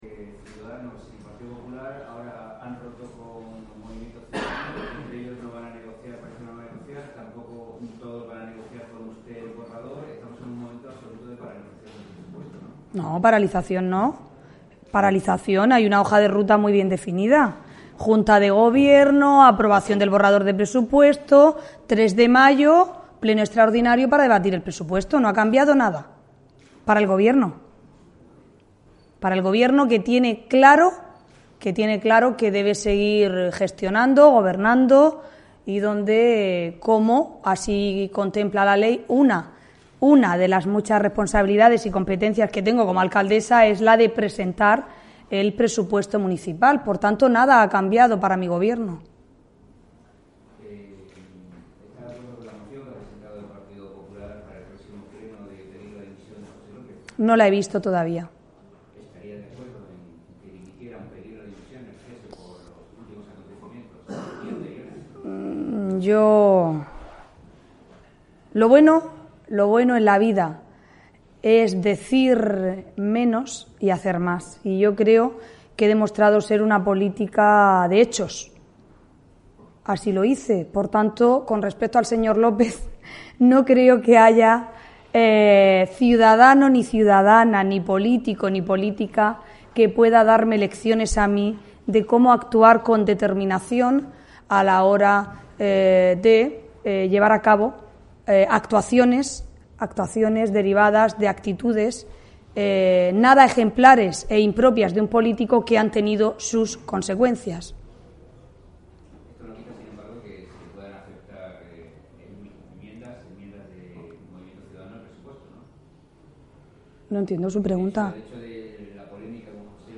Audio: Presentaci�n de los cursos para emprendedores y empresarios de Aula Innova (MP3 - 4,37 MB)
La presentación de este ciclo ha corrido a cargo de la alcaldesa de Cartagena, Ana Belén Castejón ; quien ha estado acompañada por el director del Instituto de Fomento de Murcia, Joaquín Gómez.